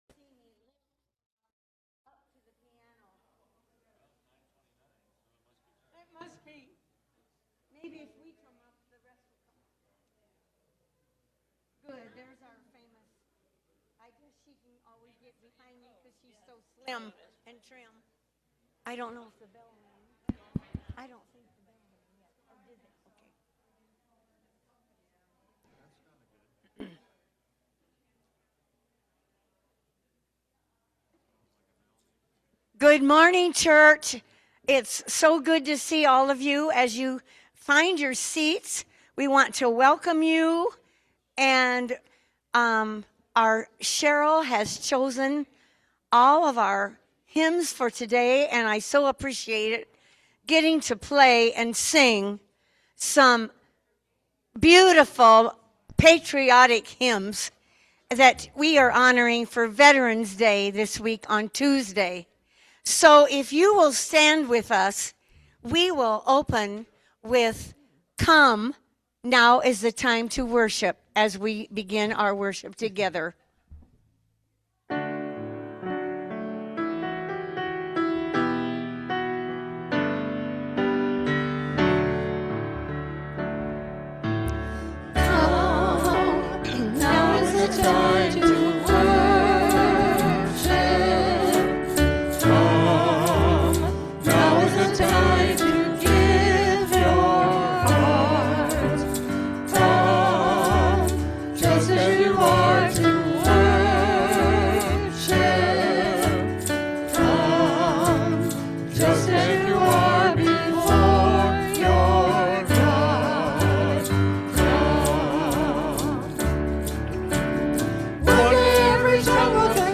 Worship-November-9-2025-Voice-Only.mp3